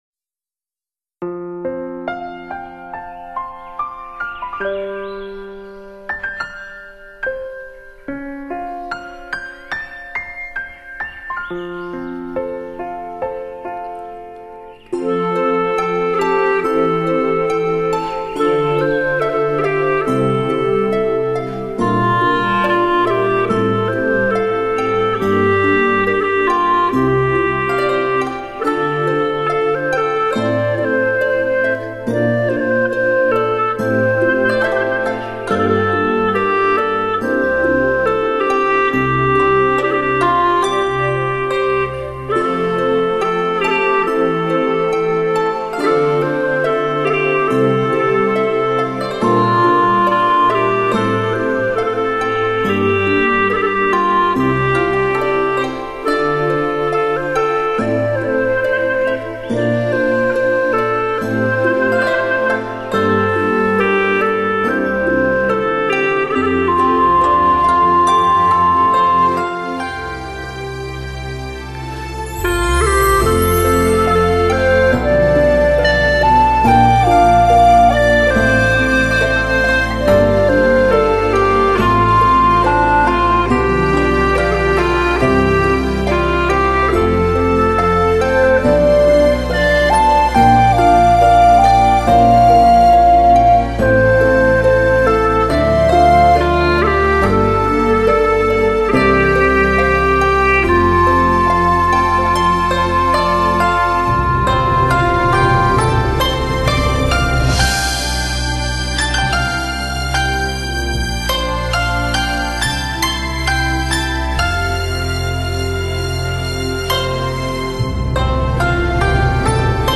葫芦丝的精美演绎与最新电影歌曲
精美的现代配器与电声乐器的完美融入